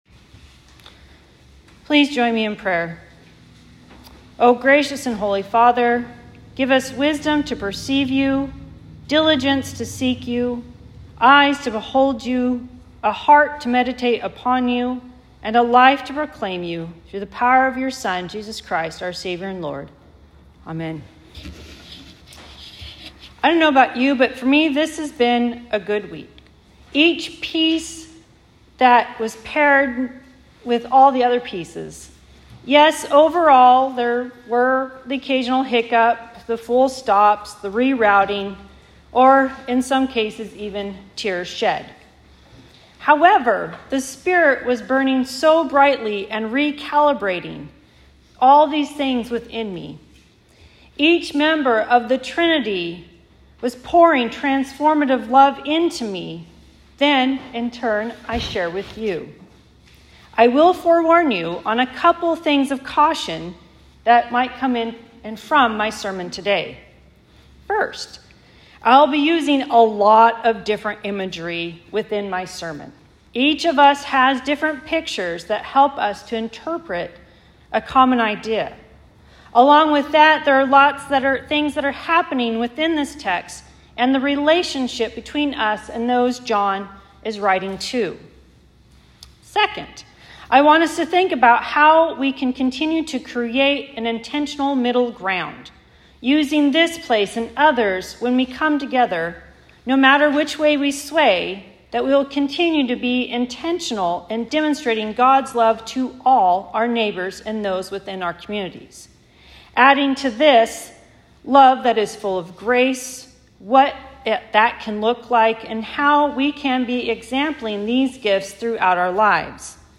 Sermons | Fir-Conway Lutheran Church